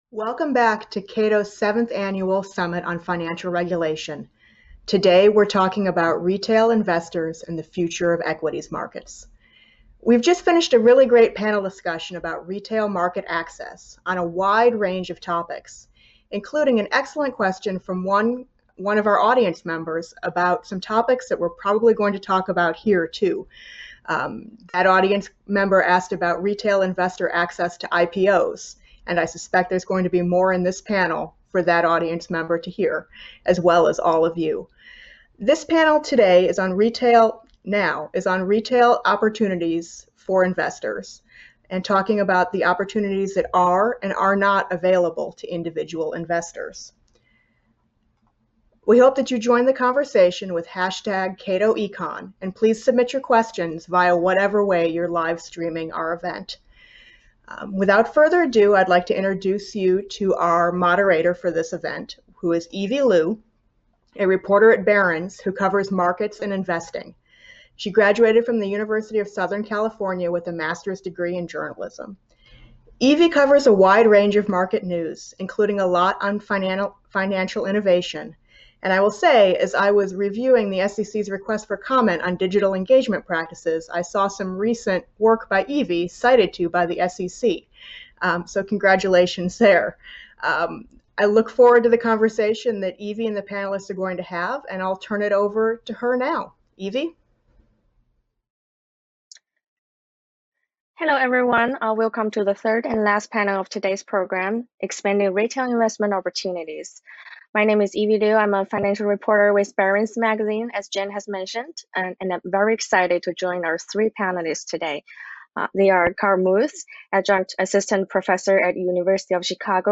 Join us for an outstanding virtual program featuring leading policymakers and experts at Cato’s seventh annual Summit on Financial Regulation.